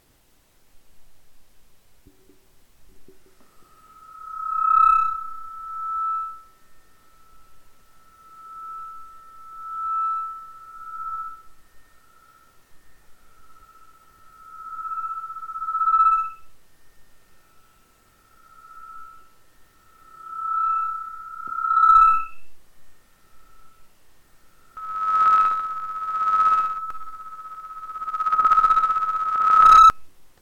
Звуки микрофона
Звук свиста при поднесении к колонкам (фонит)